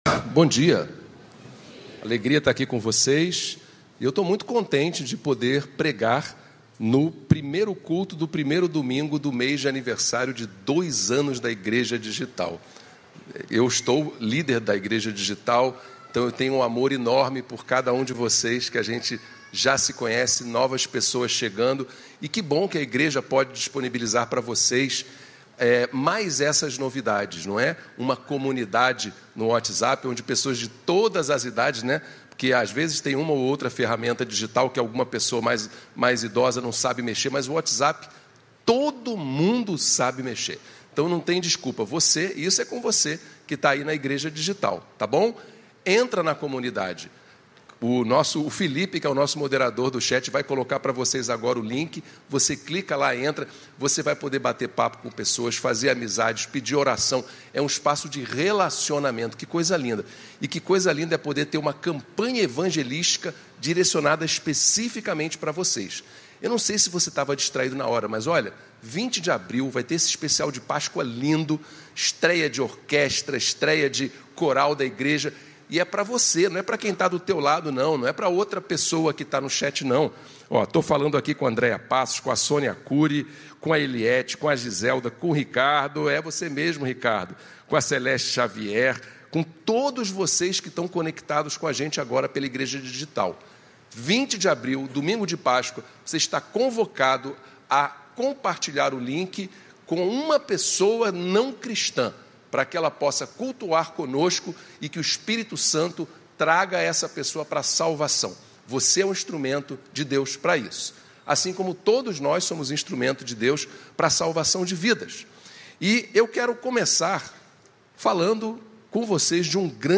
Mensagem apresentada
Igreja Batista do Recreio